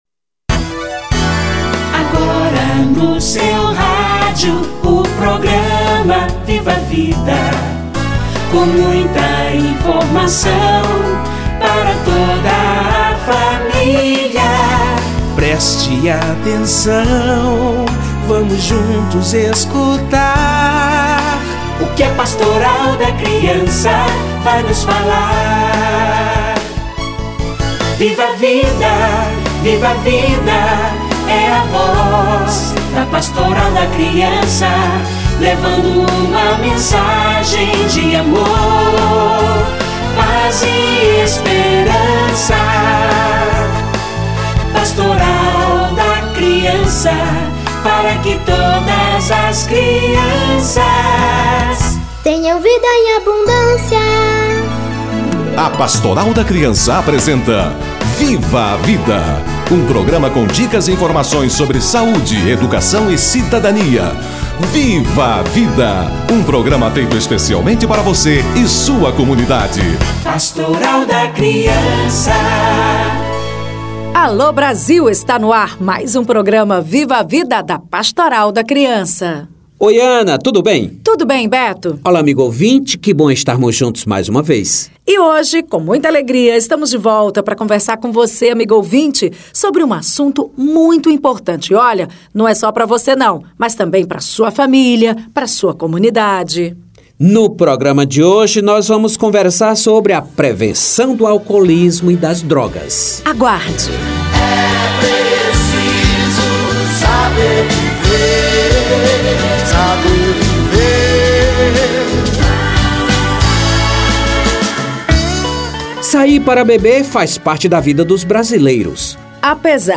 Prevenção ao uso de álcool e outras drogas - Entrevista